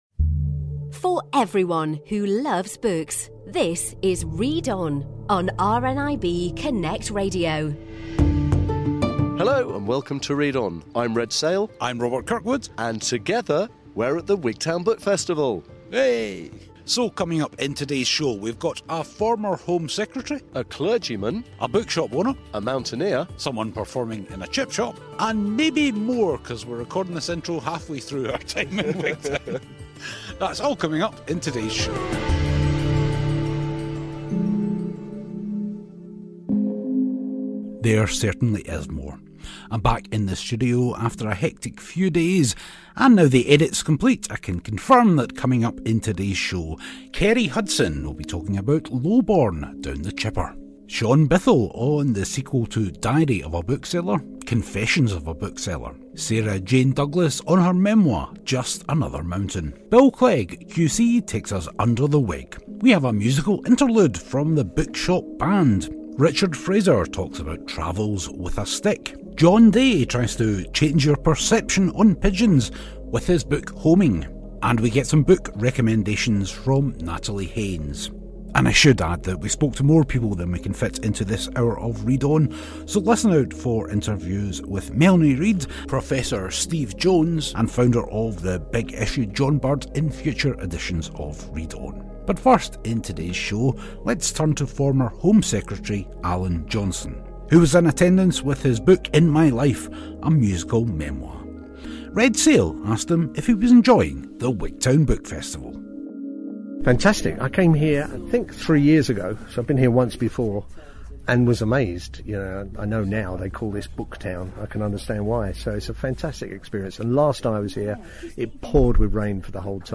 So, this week in a packed show we revisit the 2019 Wigtown Book Festival.